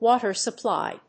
アクセントwáter supplỳ